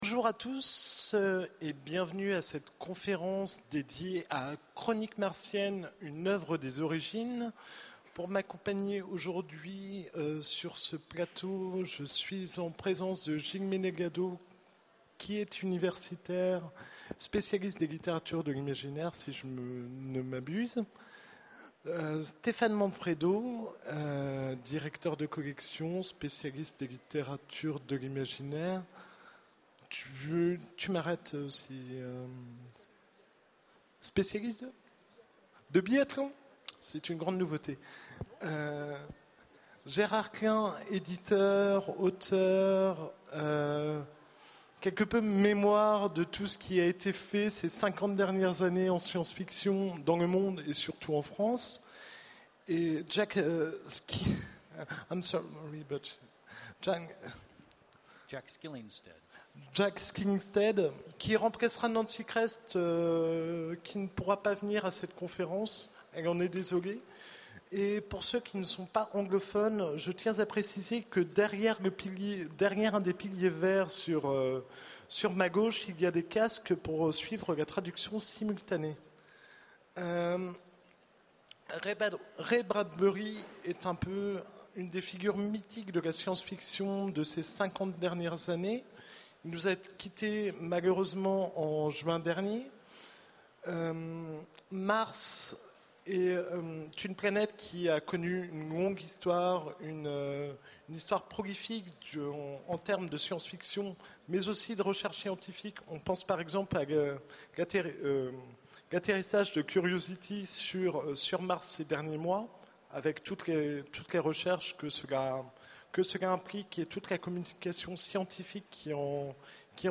Utopiales 12 : Conférence Chroniques Martiennes de R. Bradbury